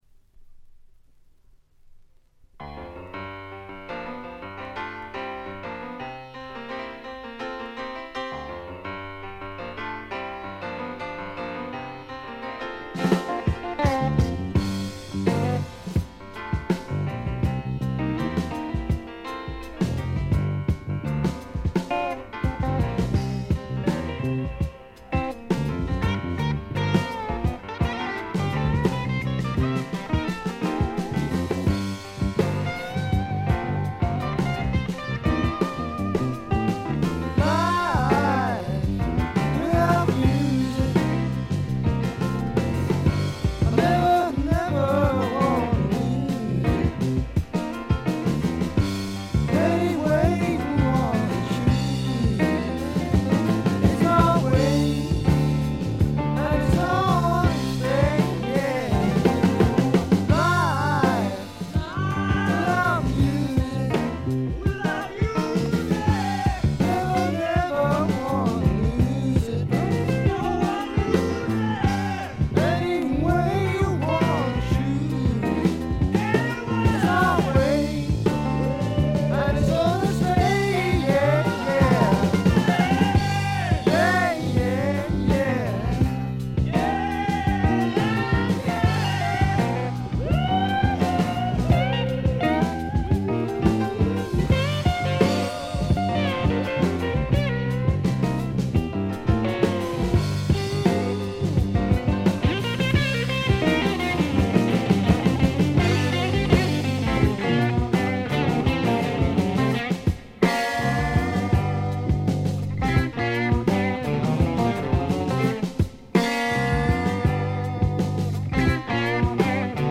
ほとんどノイズ感無し。
内容は枯れた感じのフォーク・ロック基調でちょっと英国スワンプ的な雰囲気もあり、実に味わい深いアルバムとなりました。
試聴曲は現品からの取り込み音源です。
Guitar, Piano, Violin, Vocals
Drums, Percussion